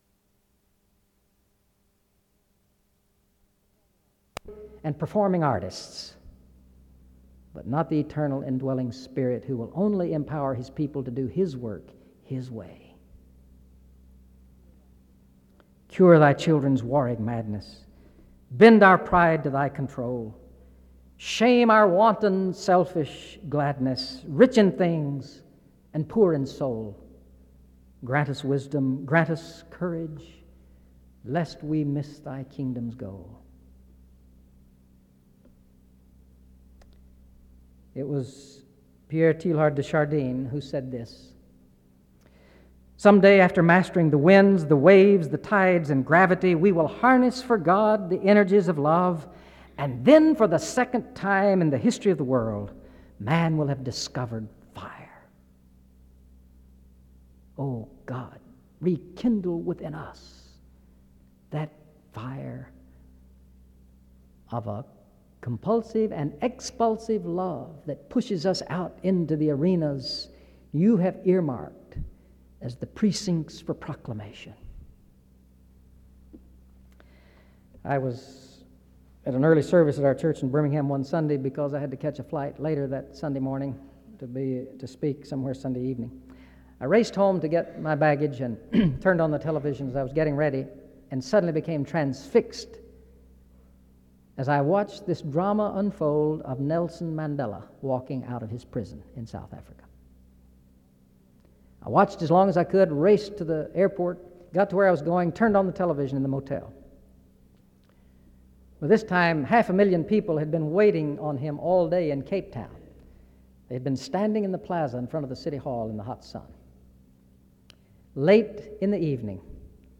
The service opens with prayer from 0:00-0:53. An introduction to the speaker is given from 1:01-2:50.